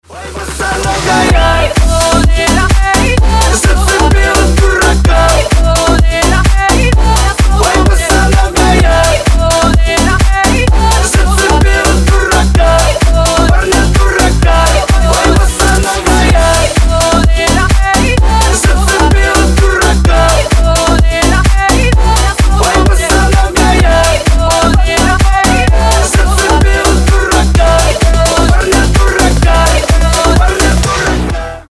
• Качество: 320, Stereo
Electronic
Club House
энергичные
Стиль: club house